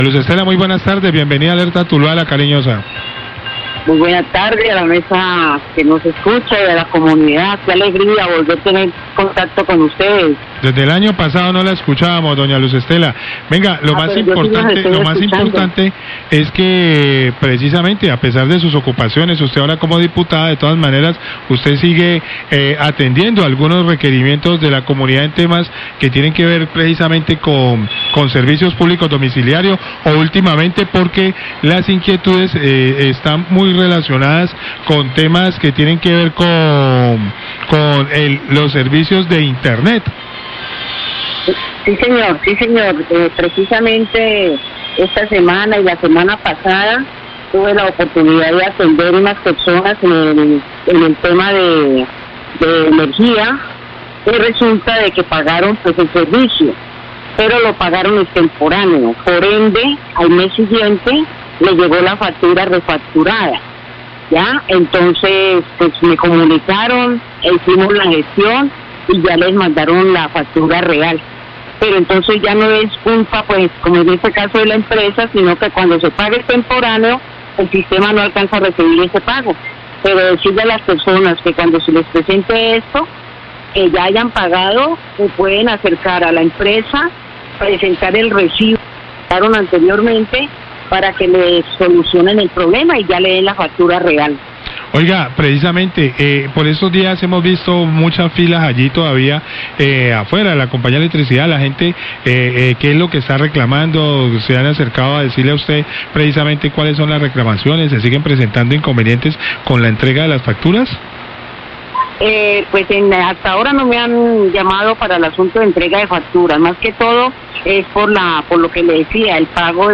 Radio
La diputada y vocal de servicios públicos, Luz Estela Sevilano, responde dudas de los oyentes relacionadas con el pago de la factura de energía.